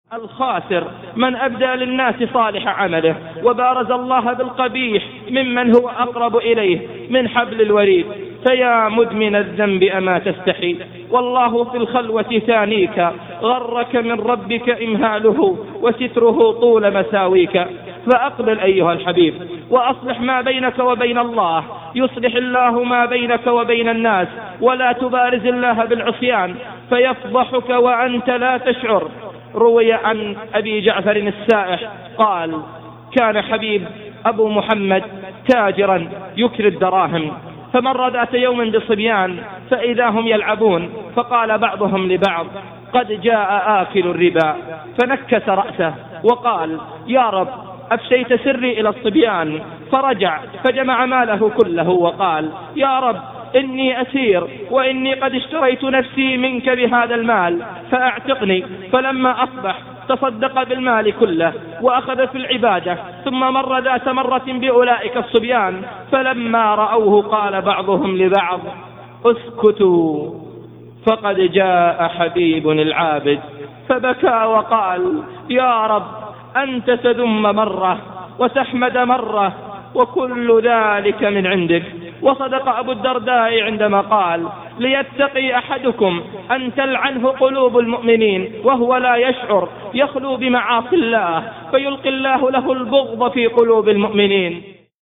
من هو هذا المُحاضِر :